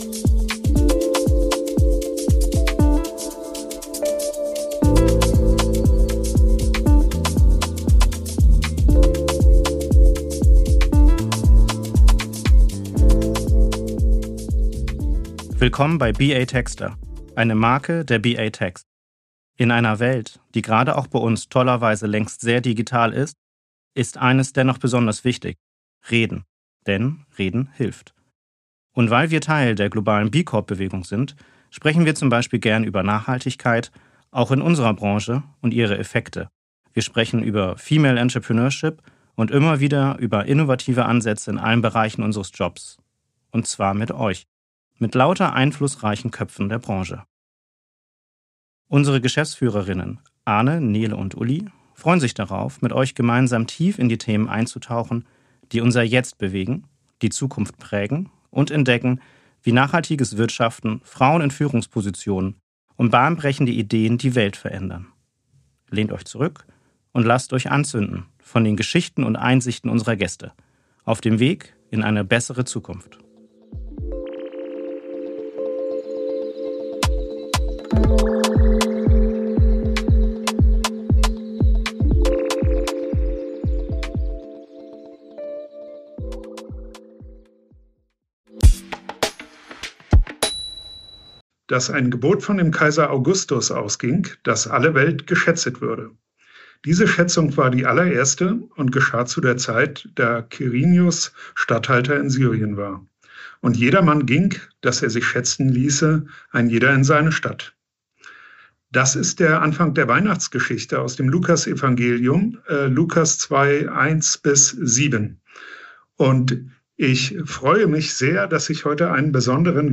Ein Gespräch zwischen Kanzel und Kanzlei.